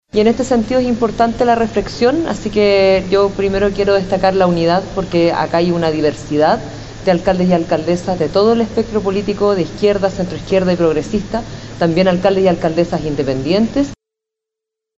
Por su parte, la alcaldesa de Quinta Normal, Karina Delfino, destacó la importancia de la diversidad territorial y política presente en el encuentro entre las autoridades municipales.